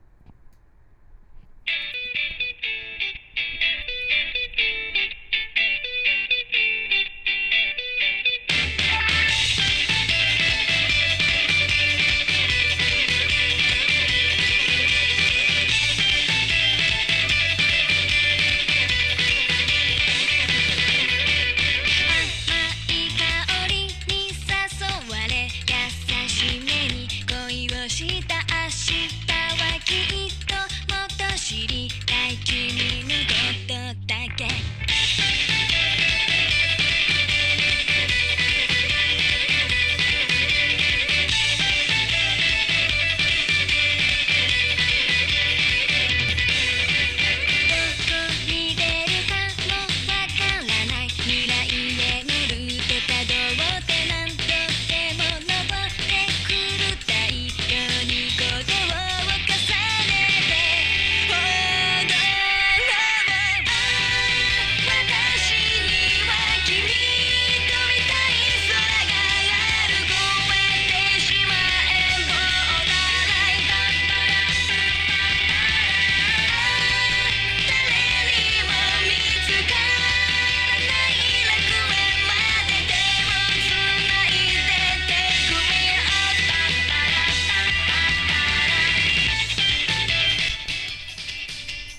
・ステレオICレコーダー：オリンパス LS-20M リニアPCM 44.1kHz/16bit 非圧縮WAV形式